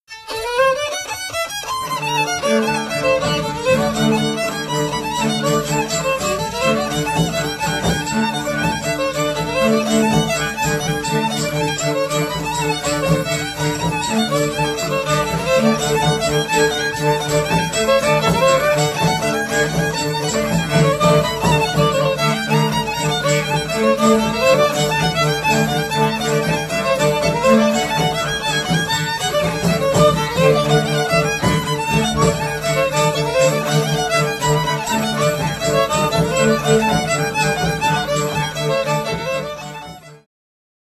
Oberek
Badania terenowe
skrzypce
basy 3-strunowe
bębenek